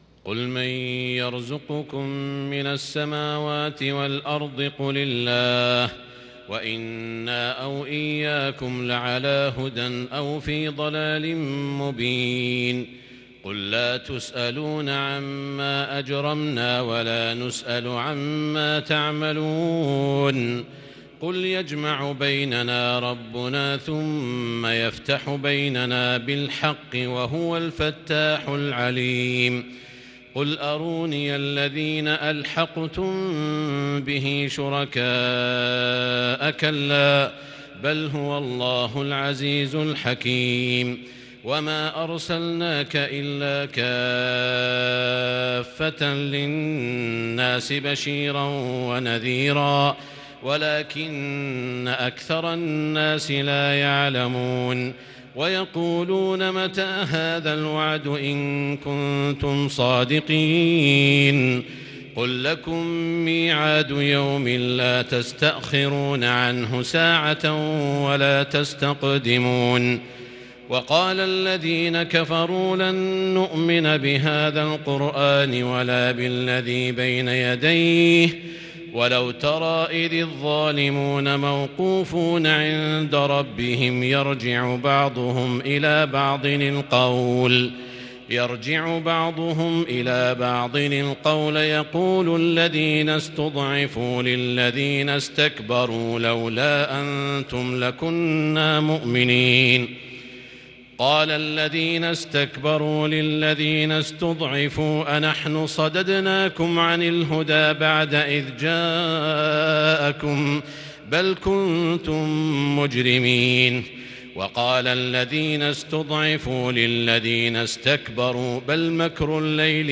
تراويح ليلة ٢٥ رمضان ١٤٤١هـ من سورتي سبأ 24-54 و فاطر كاملة | taraweeh 25 st night Ramadan 1443H Surah Saba & Faatir 1441H > تراويح الحرم المكي عام 1441 🕋 > التراويح - تلاوات الحرمين